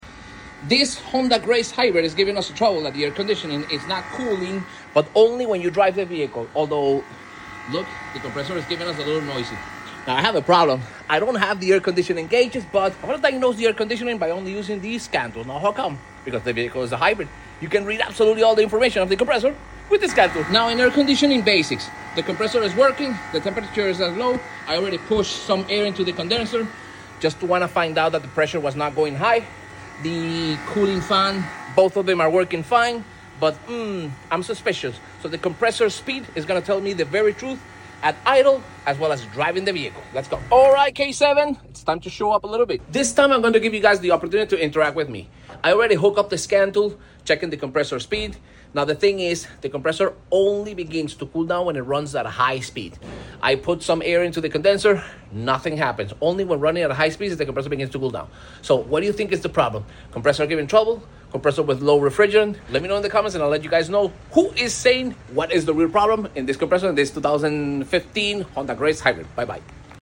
Honda Grace Hybrid with Noisy AC Compressor? 😱